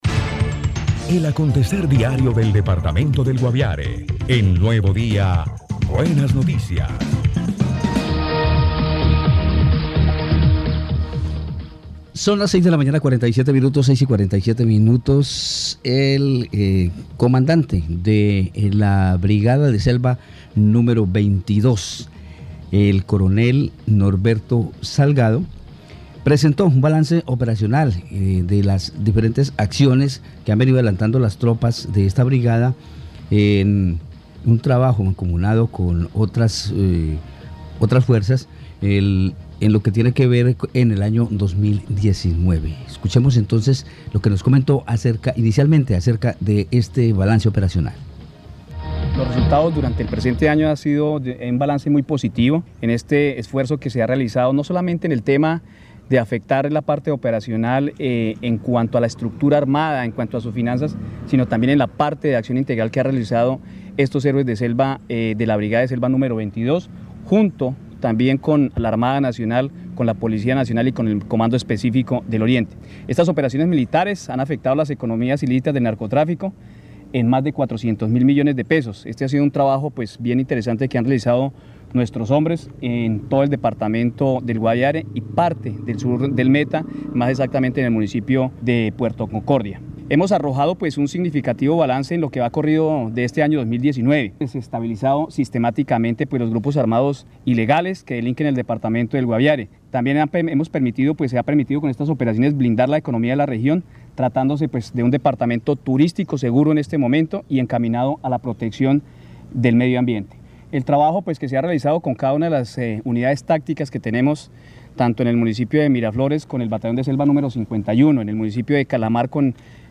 El alto oficial en rueda de prensa entregó el balance operacional de las unidades que se encuentran en el departamento del Guaviare.
Escuche al Coronel Norberto Salgado, comandante de la Vigésima Segunda Brigada de Selva.